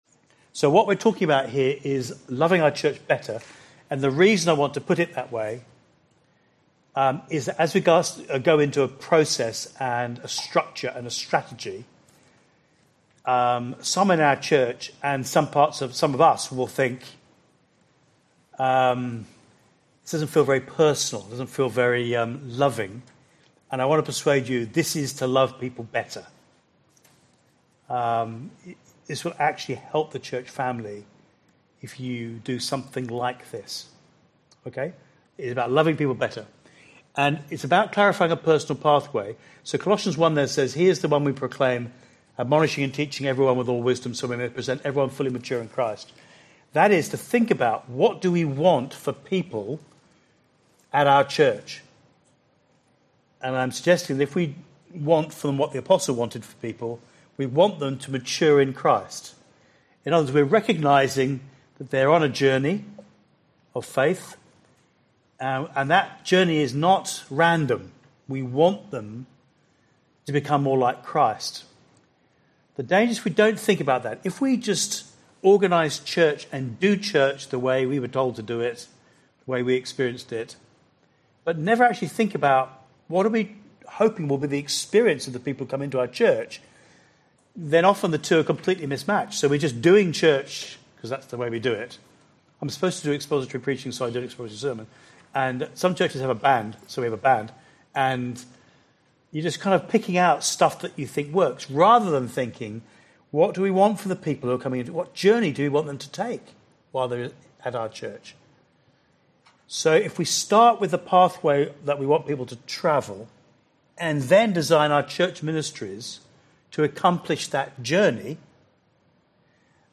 seminar